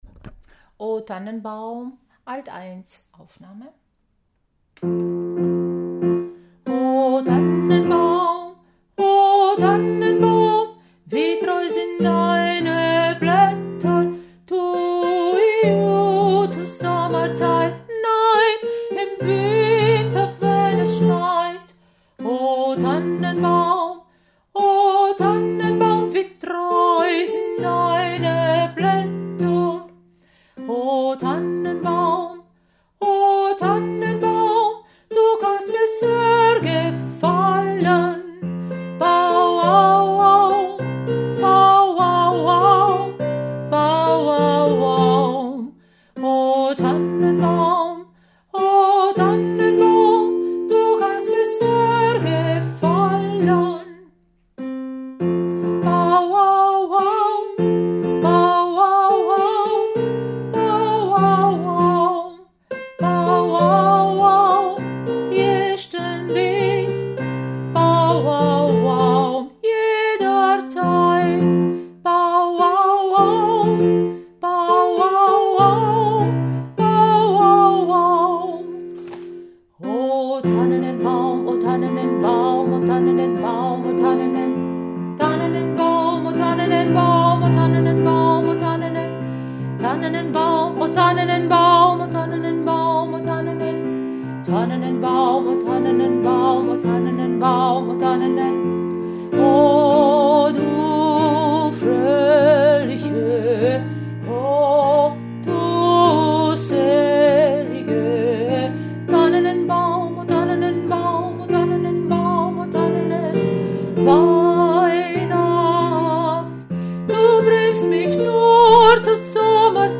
Oh Tannenbaum Alt 1
Oh-Tannenbaum-Alt1.mp3